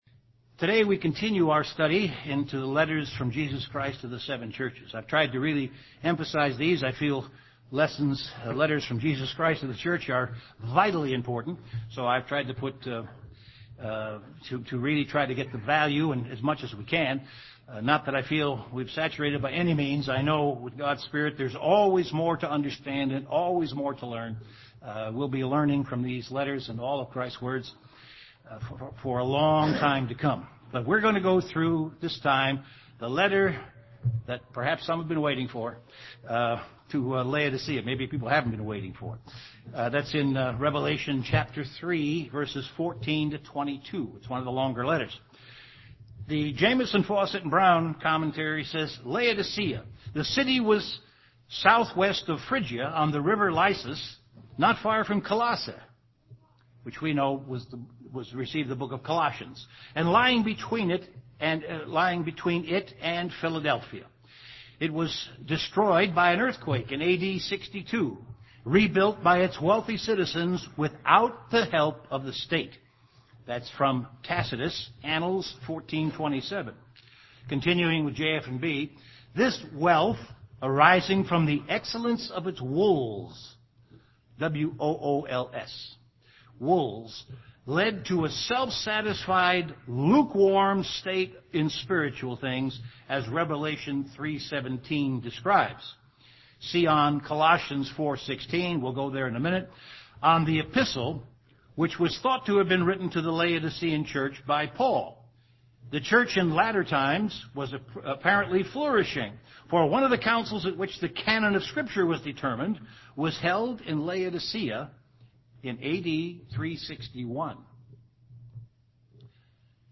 Given in Chicago, IL Beloit, WI
UCG Sermon